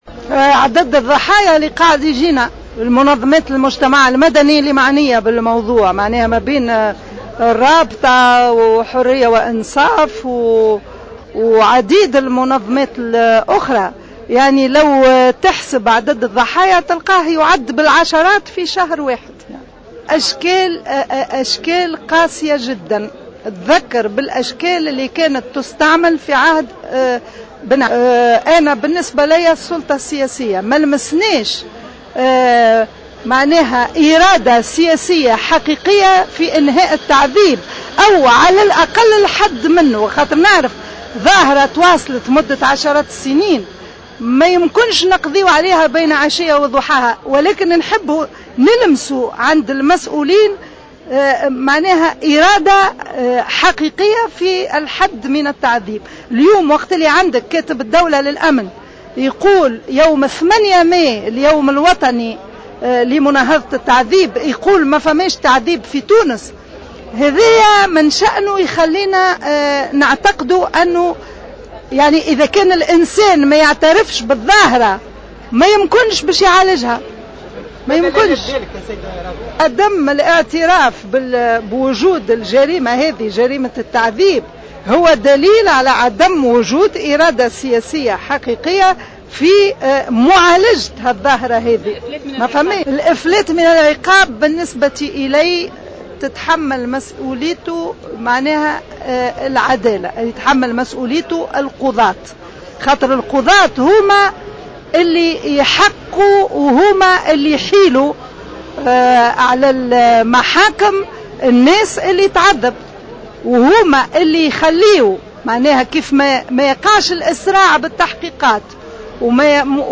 أكدت رئيسة الجمعية التونسية لمناهضة التعذيب راضية النصراوي على هامش تجمع بمناسبة الإحتفال باليوم العالمي لمساندة ضحايا التعذيب أن الحالات التي تعرضت للتعذيب والتي ترد يوميا على منظمات المجتمع المدني المعنية بهذا الشأن في تزايد .